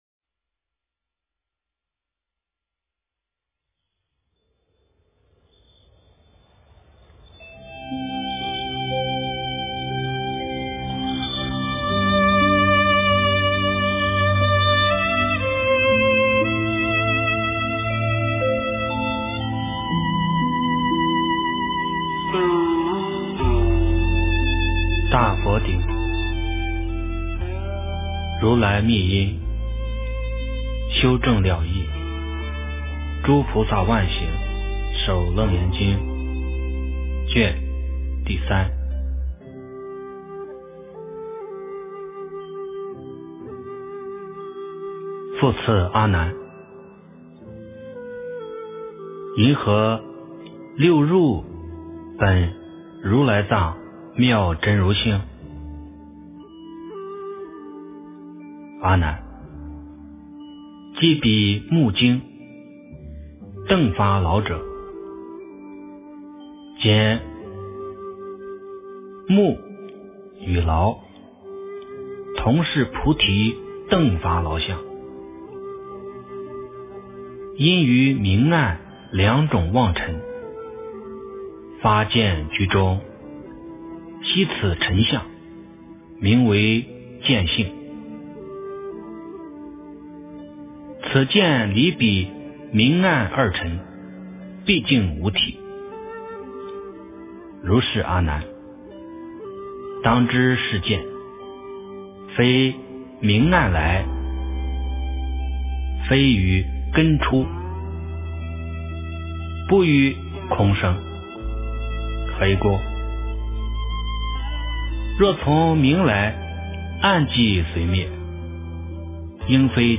楞严经第03卷（念诵）
诵经